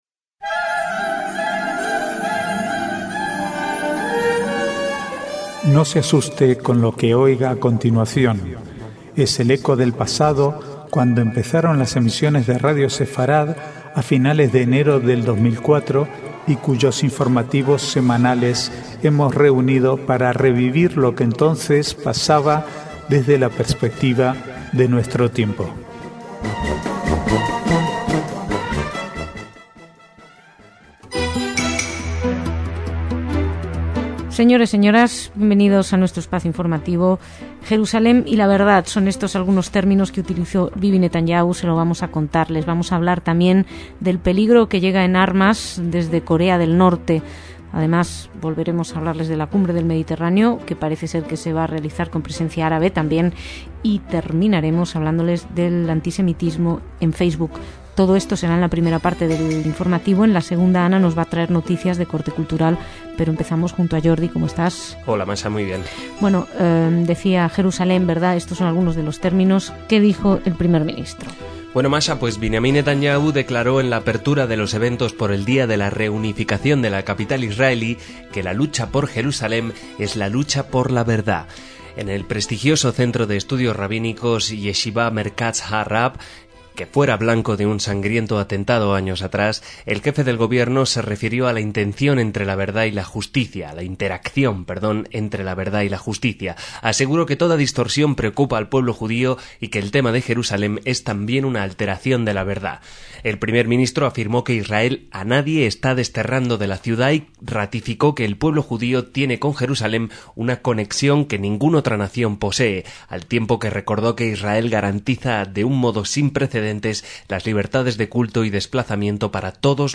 Archivo de noticias del 13 al 19/5/2010